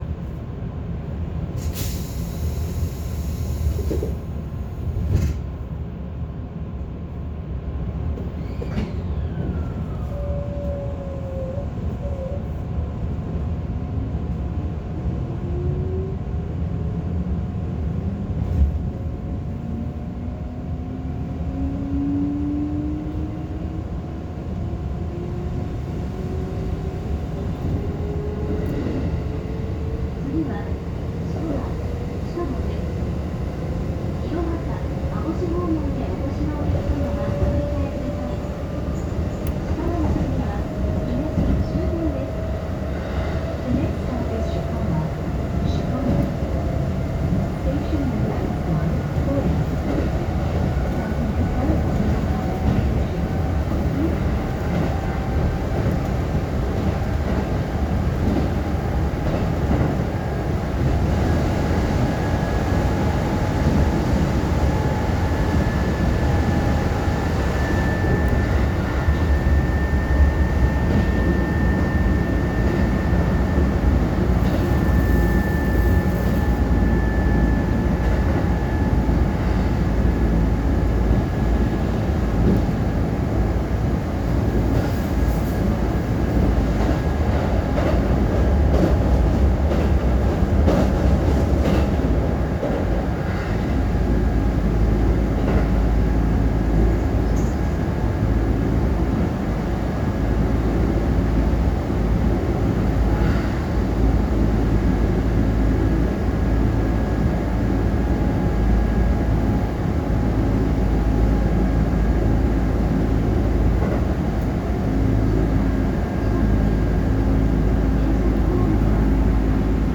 ・5000系走行音
【本線】白浜の宮→飾磨
5030系が連結されている部分を除けば界磁となっており、とても味のある音を立ててくれます。